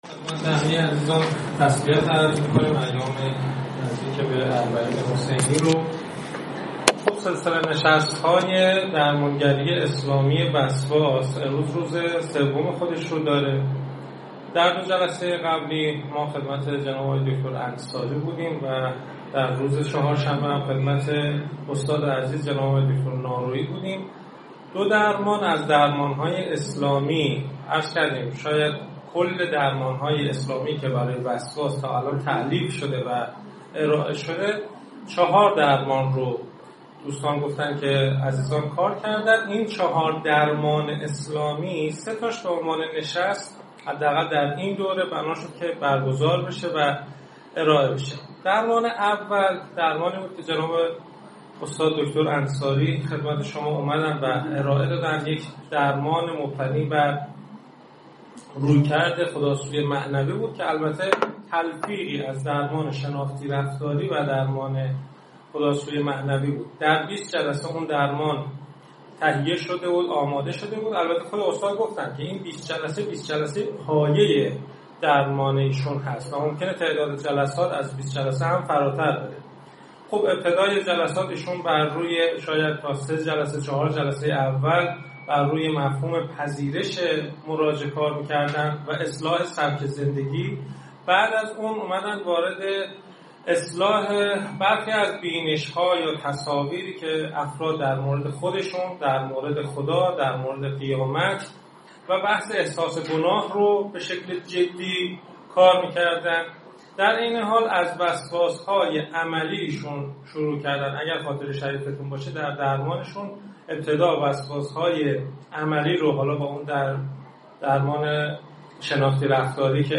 متن این نشست تخصصی تقدیم شما فرهیختگان می شود: